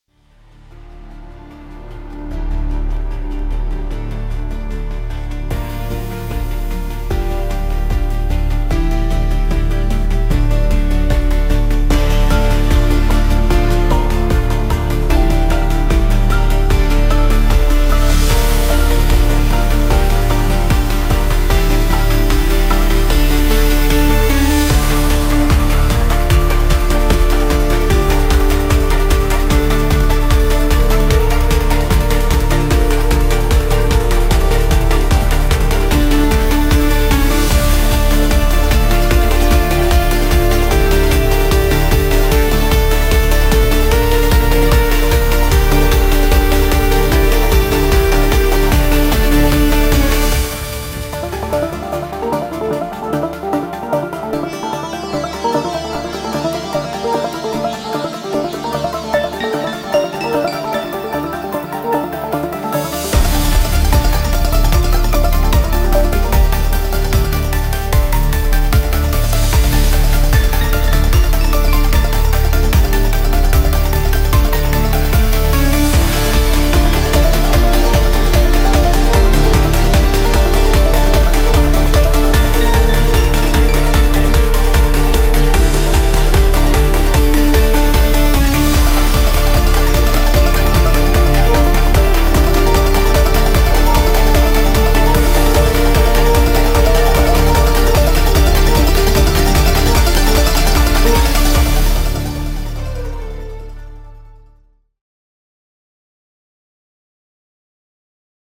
100 inspired high quality sci-fi Pluck presets.
additional arpeggiators and pads from Omnisphere